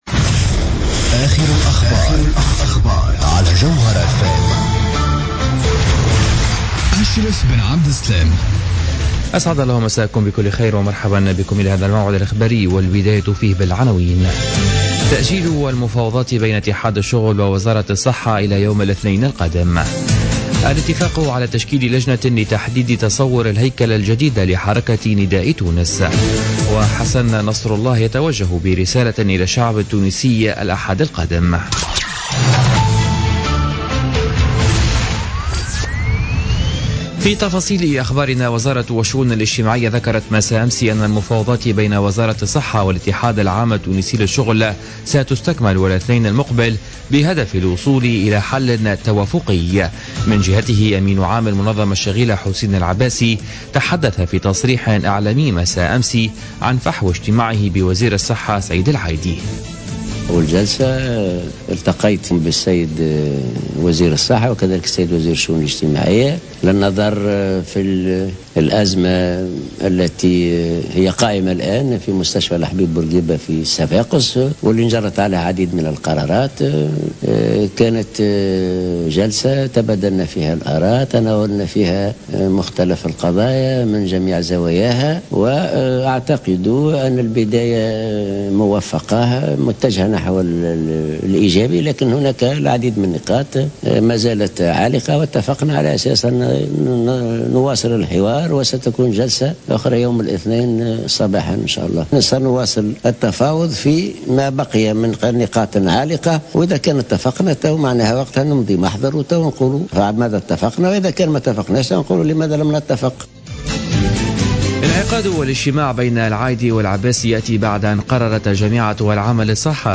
نشرة أخبار منتصف الليل ليوم السبت 5 مارس 2016